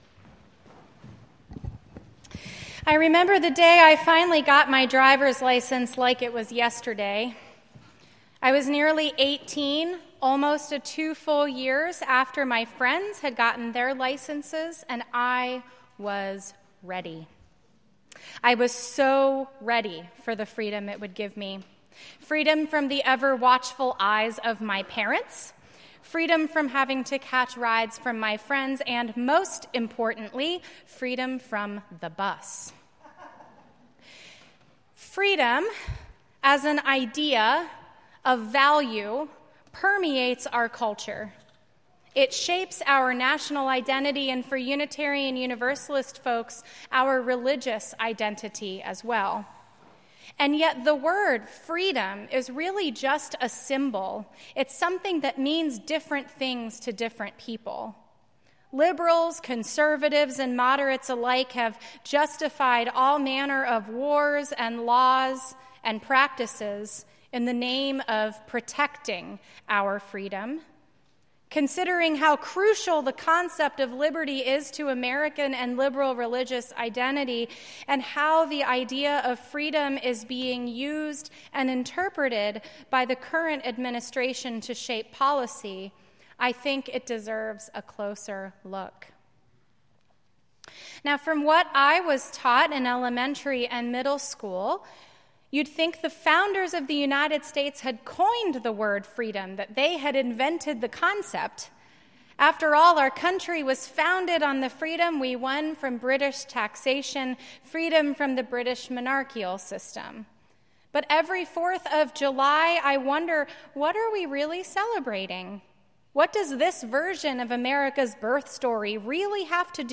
9:30 and 11:15 a.m. Our free faith is sometimes mischaracterized as a license to do and believe "whatever we want" and our congregations are places where "anything goes." This week in worship we will reflect on the roots of our Free Church tradition and consider not just what our identity as liberal religious people frees us from but what it frees us for.